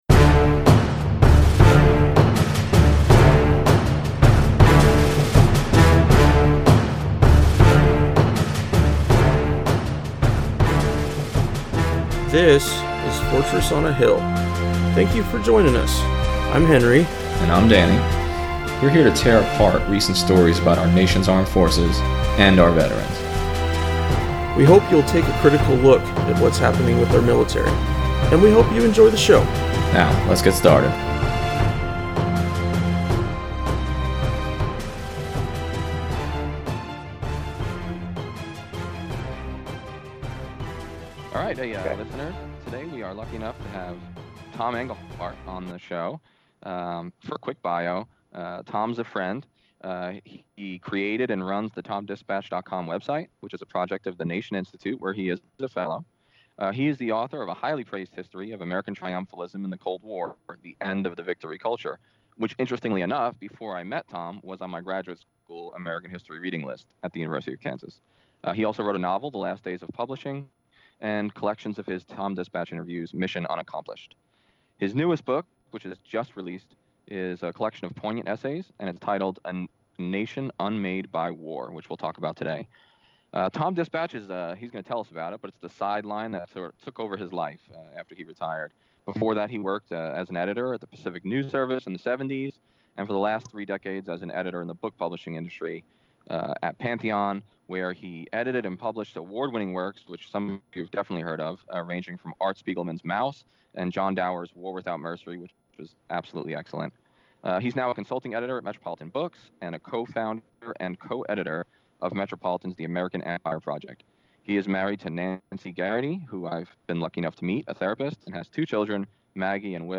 Tom Engelhardt of TomDispatch interview Ep 13.5 – Part 1